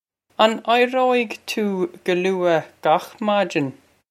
Pronunciation for how to say
On eye-ro-ig too guh loo-a gokh modge-in?
This is an approximate phonetic pronunciation of the phrase.